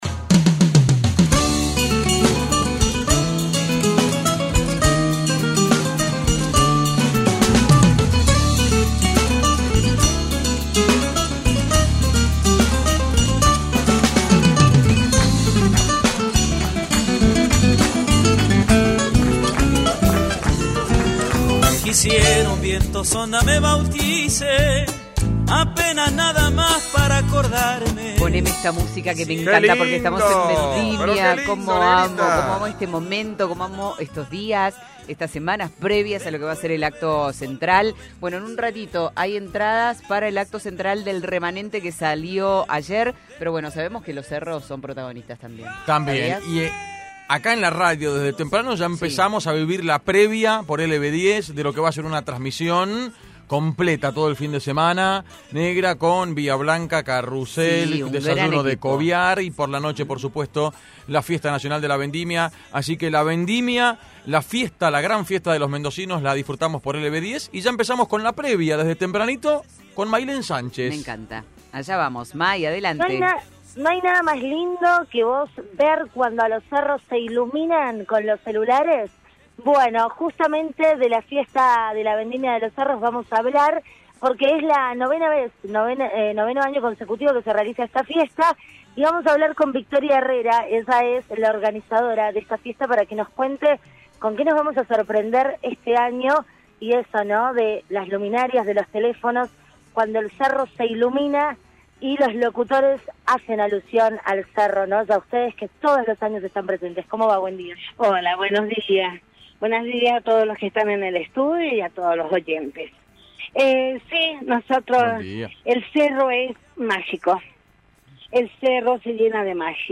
Movil de LVDiez- “Entre Condores y golondrinas” la Fiesta de la Vendimia de los Cerros